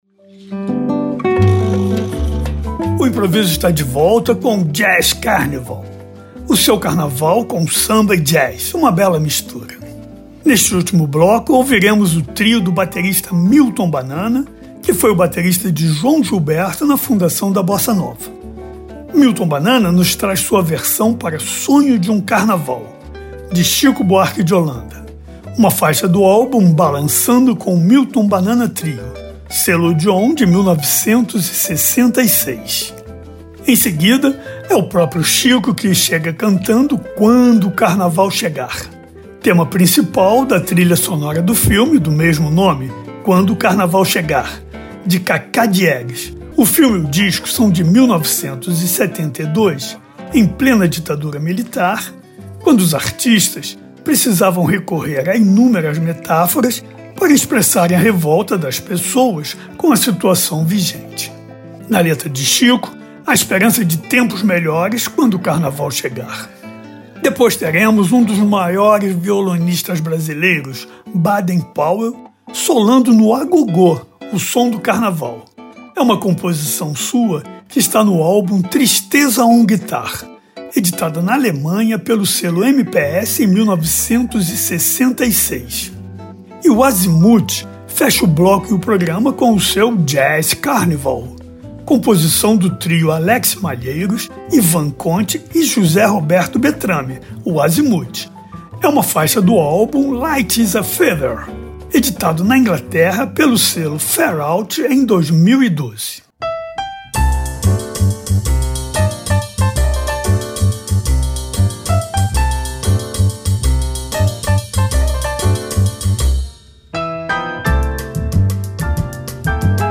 Tópicos: Jazz Samba